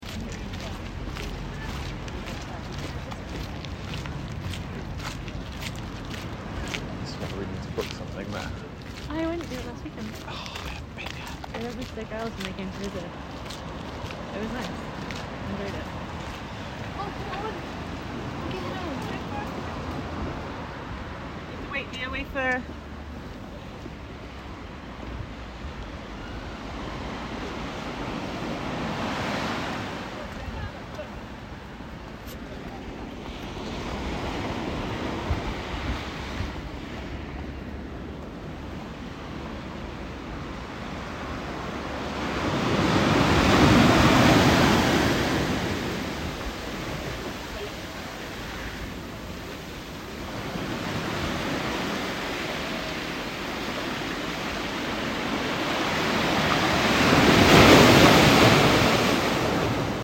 Sounds from home (elsewhere) are overlaid and geo-located along the canal.